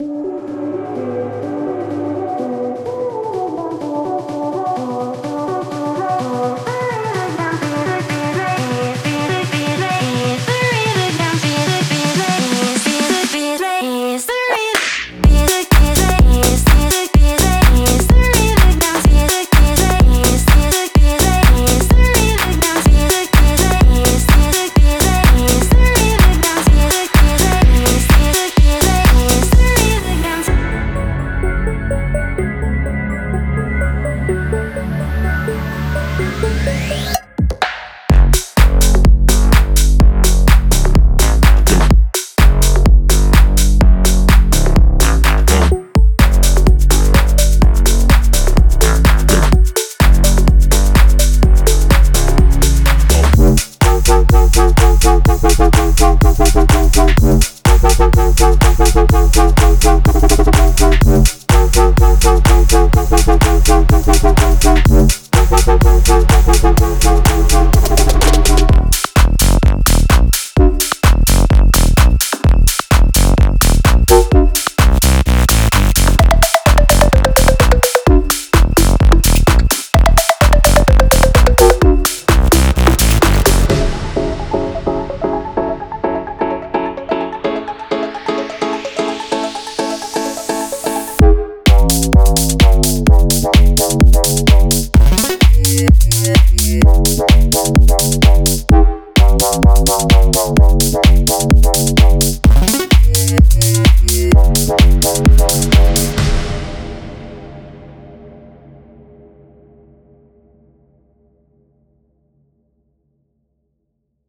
低音屋是目前最激动人心的舞蹈音乐流派之一，它结合了沉重的低音和来自各种风格的电子舞蹈音乐的
和悬念的滴落声，创造了前所未有的舞池节奏。
141个鼓
·5个人声